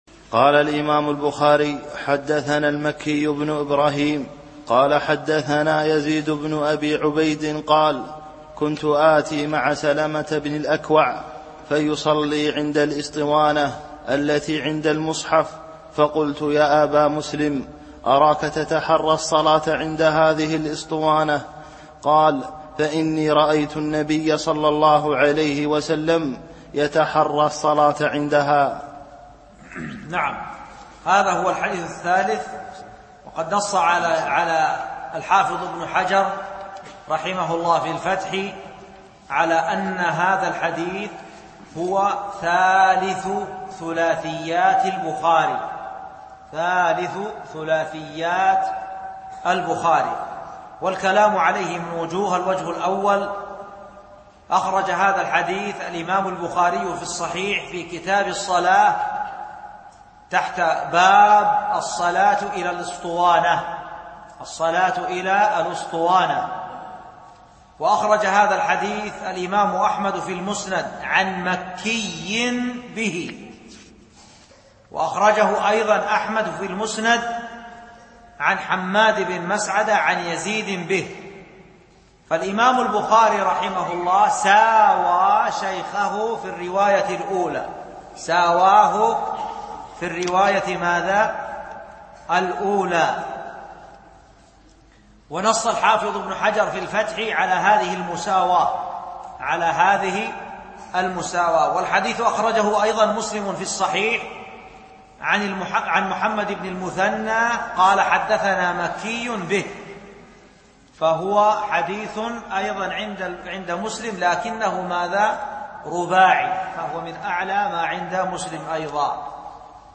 التعليق على الحديث (3) [502] (كنت آتي مع سلمة بن الأكوع فيصلي عند الأسطوانة التي عند المصحف فقلت يا أبا مسلم أراك تتحرى...)
الألبوم: شبكة بينونة للعلوم الشرعية المدة: 10:44 دقائق (2.5 م.بايت) التنسيق: MP3 Mono 22kHz 32Kbps (VBR)